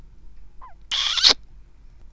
bisou.wav